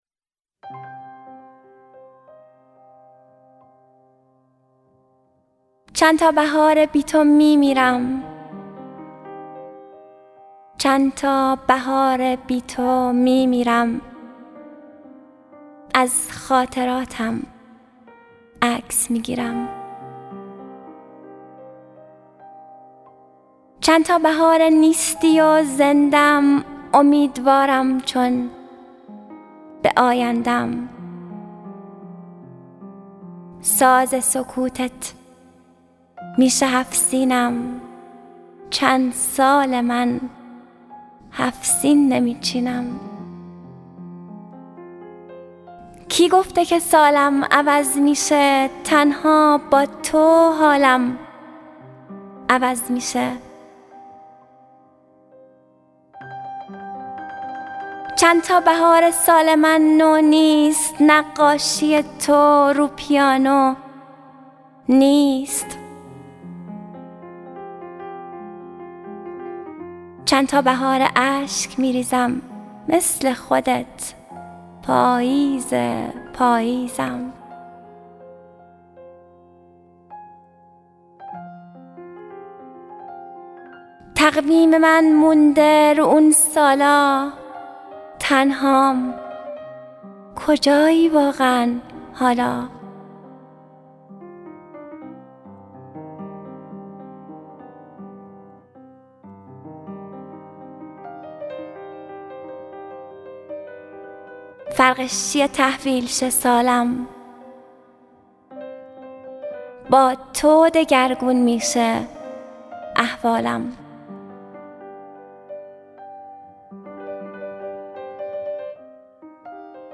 اطلاعات دکلمه
گوینده :   [مریم حیدرزاده]